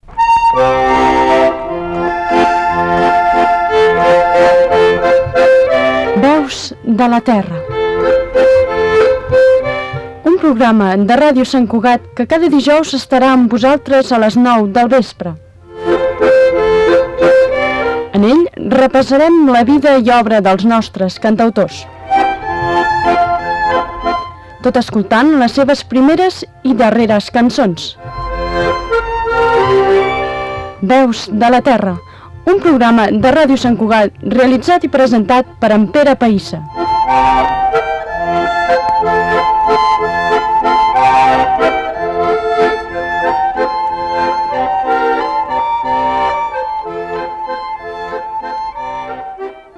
Falca de promoció del programa
FM